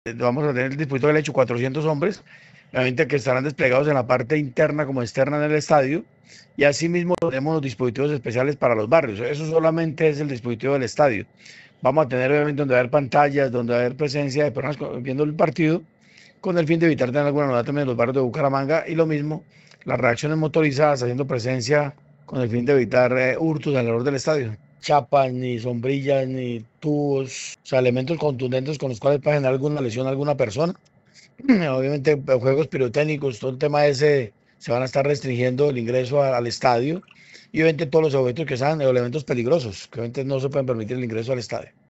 General William Quintero, comandante Policía Metropolitana de Bucaramanga